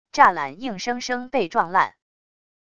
栅栏硬生生被撞烂wav音频